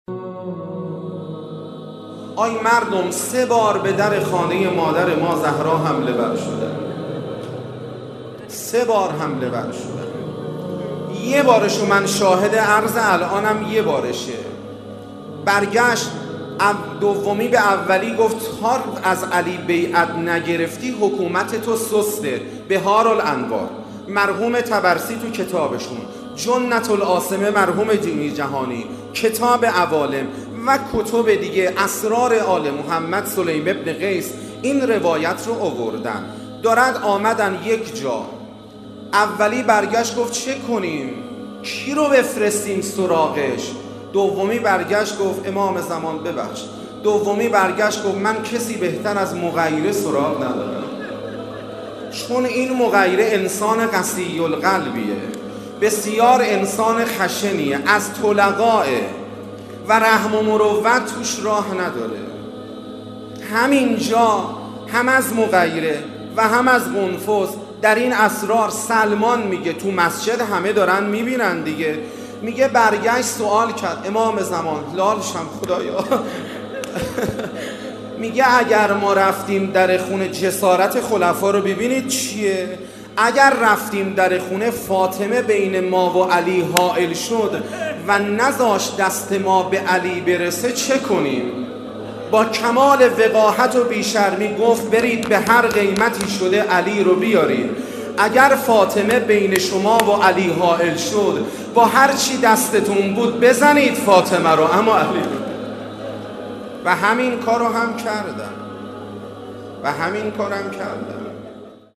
شهادت حضرت زهرا (س) آموزه‌ای متعالی در باب دفاع از آرمان‌های دینی و ارزش‌های اسلام است. ایکنا به مناسبت ایام سوگواری شهادت دخت گرامی آخرین پیام‌آور نور و رحمت، مجموعه‌ای از سخنرانی اساتید اخلاق کشور درباره شهادت ام ابیها(س) با عنوان «ذکر خیر ماه» منتشر می‌کند.